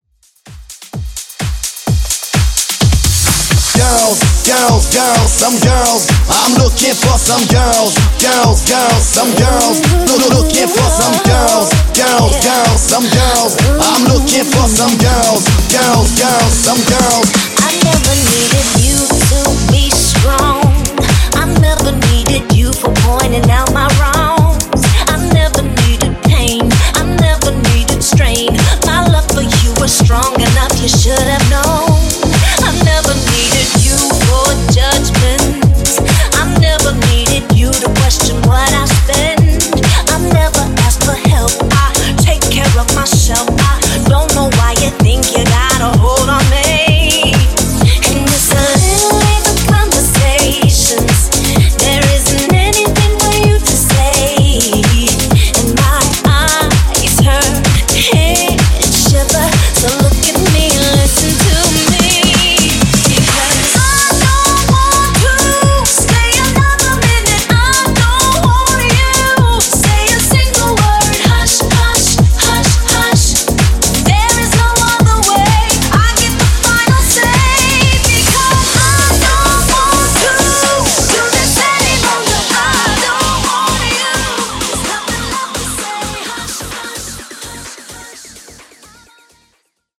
Bootleg Rework)Date Added